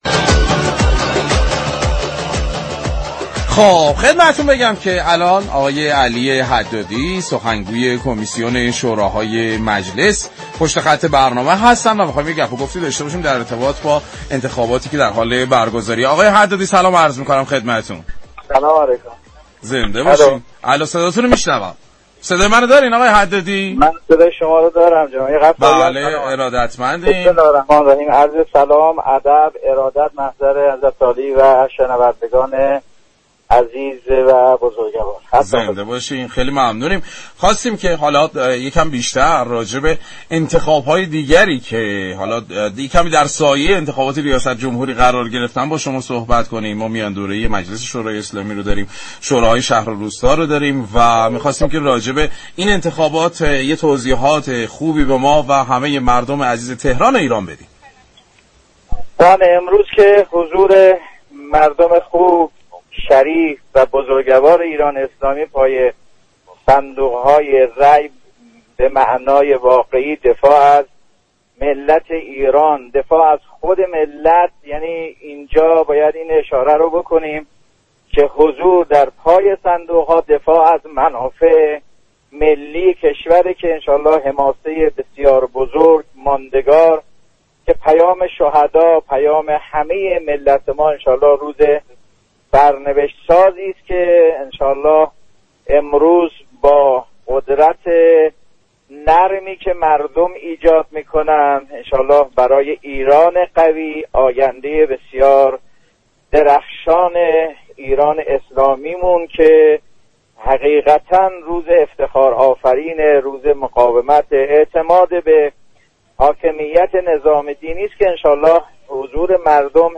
به گزارش پایگاه اطلاع رسانی رادیو تهران، علی حدادی سخنگوی كمیسیون امور داخلی و شوراهای مجلس در گفتگو با خیابان ملت رادیو تهران گفت: حضور مردم در پای صندوق‌های رأی به معنای واقعی دفاع از منافع ملی كشور است امروز روز سرنوشت سازی است كه با قدرت نرمی كه مردم ایجاد می‌كنند، برای ایران قوی آینده‌ای درخشانی رقم خواهد خورد و حماسه دیگری در تاریخ كشورمان ثبت خواهد شد.